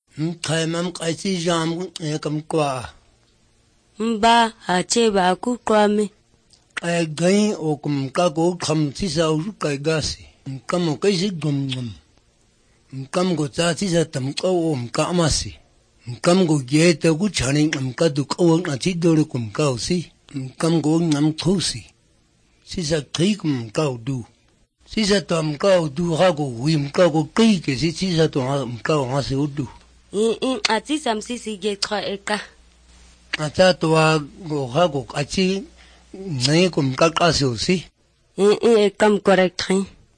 6 May 2012 at 11:21 am Are these the click consonants of the Khoisan languages?